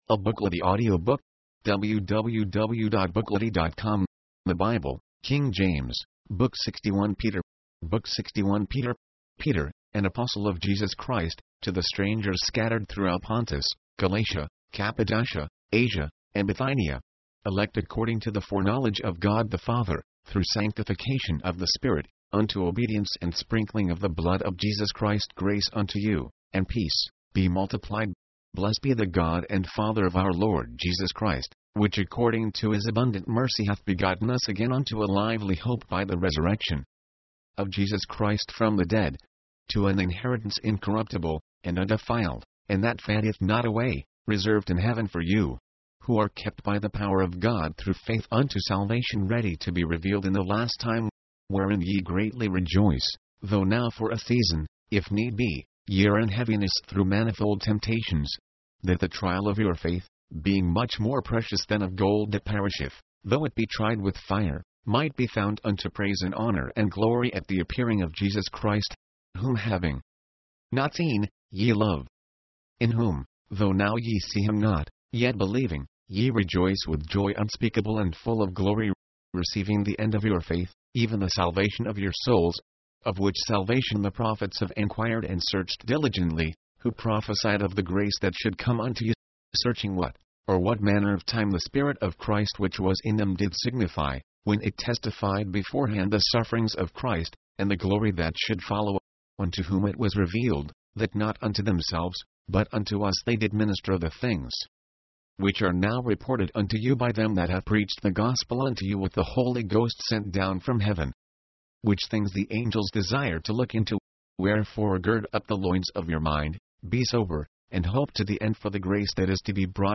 Christians must not be surprised at the fiery ordeal they face, but should resist the devil and cast their cares upon God. mp3, audiobook, audio, book Date Added: Dec/31/1969 Rating: Add your review